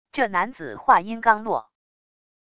这男子话音刚落wav音频生成系统WAV Audio Player